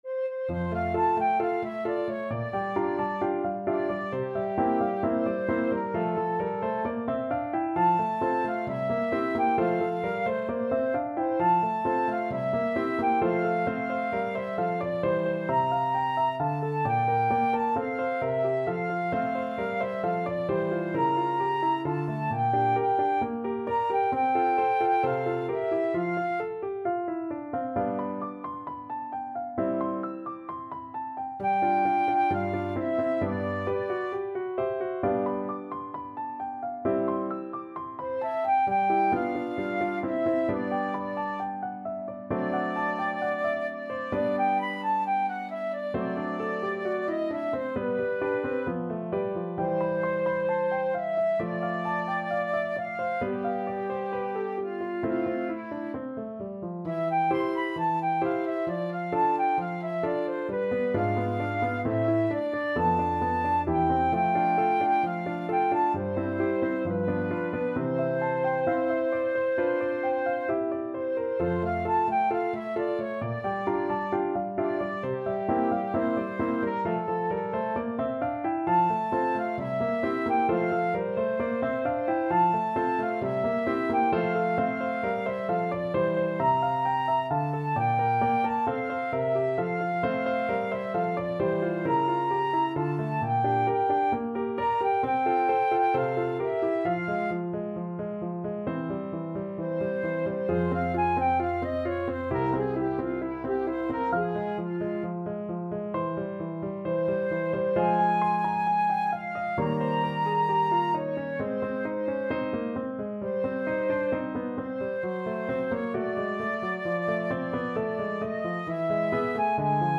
Flute
2/2 (View more 2/2 Music)
F major (Sounding Pitch) (View more F major Music for Flute )
=132 Allegro assai (View more music marked Allegro)
Classical (View more Classical Flute Music)